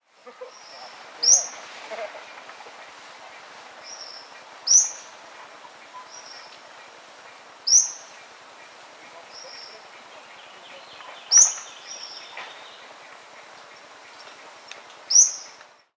Tolmomyias sulphurescens
Yellow-olive Flycatcher
[ "canto" ]